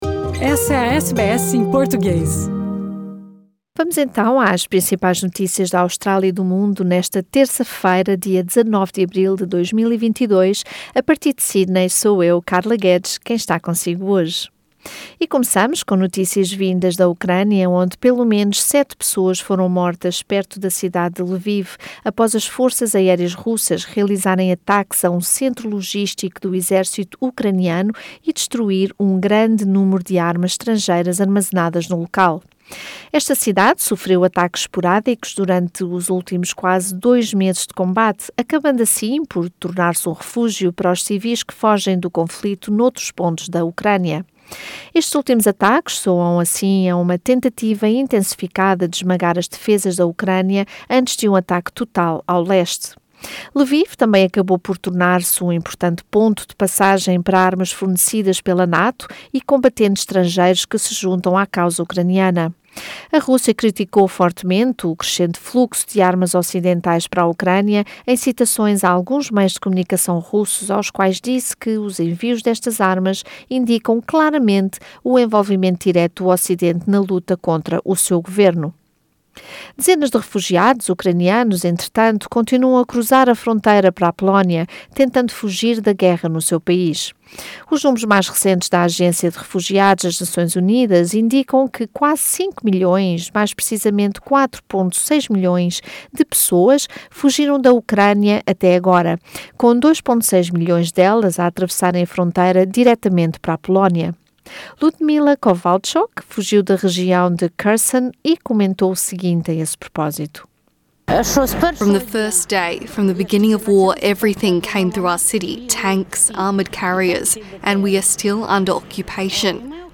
São estes alguns dos principais destaques do noticiário de hoje, dia 19 de abril de 2022.